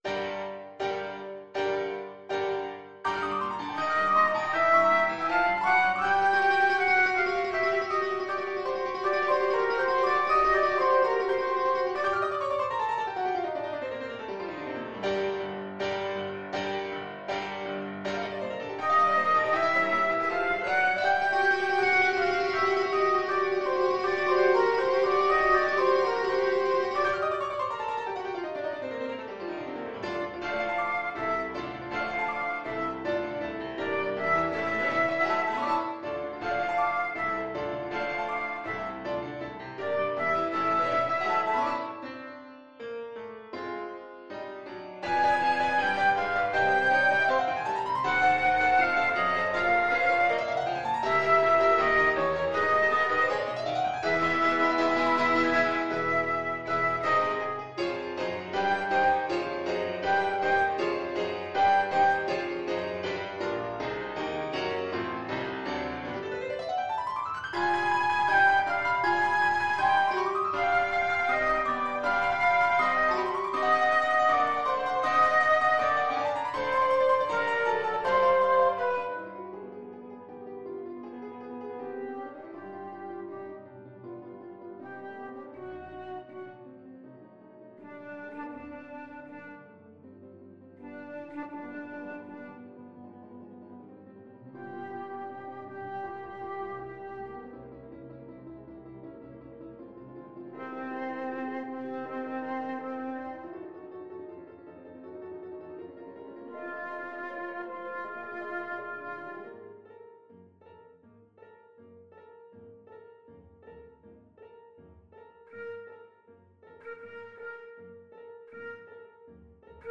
Flute version
4/4 (View more 4/4 Music)
Allegro agitato (=80) (View more music marked Allegro)
Classical (View more Classical Flute Music)